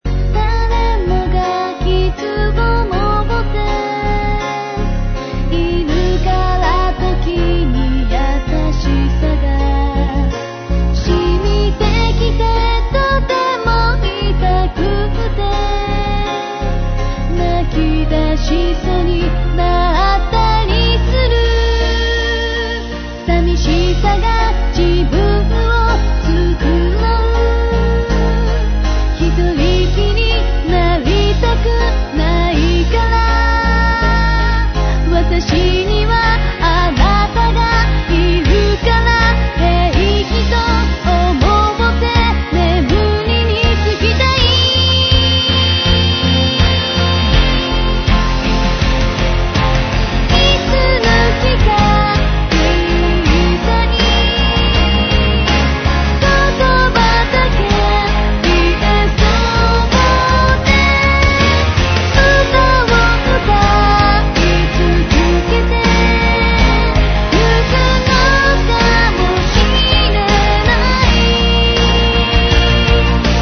Genre : Techno/Dance/Electro-Pop